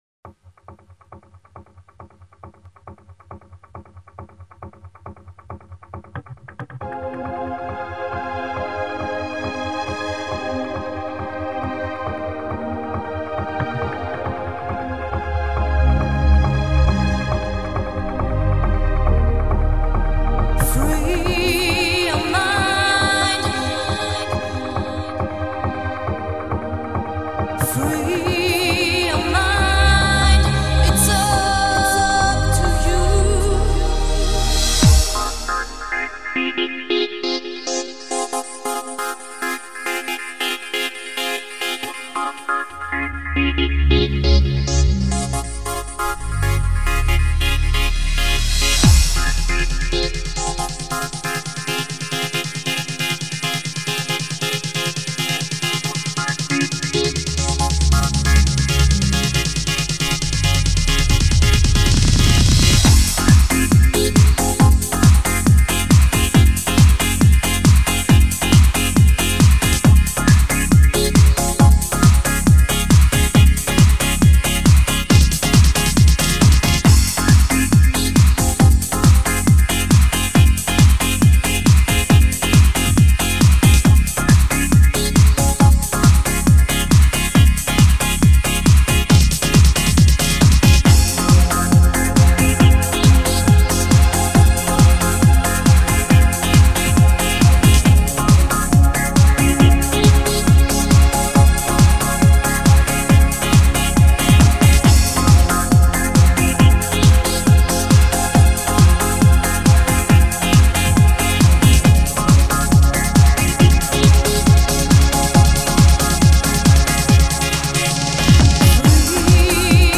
Genre : Dream Progressive
1 Mixed Track